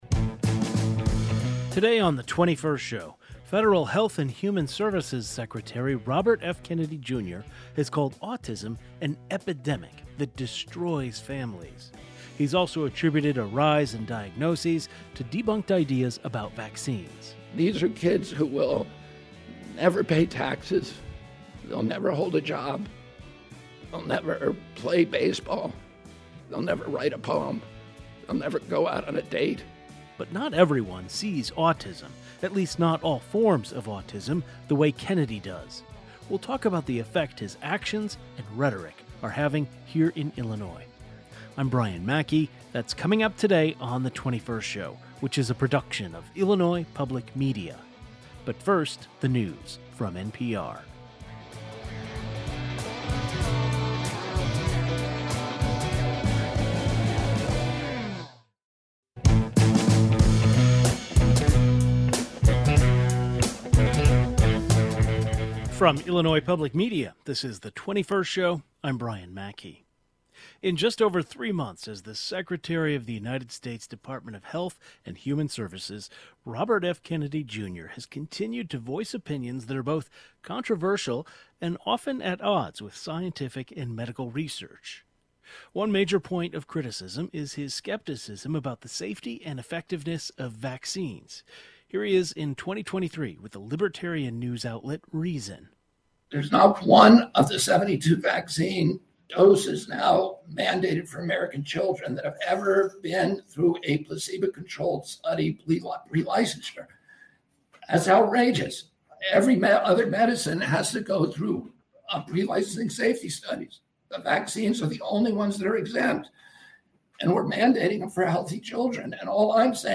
We’ll talk about the effect that rhetoric is having in Illinois with autism advocates, the parent of an autistic child, and an expert in developmental psychology.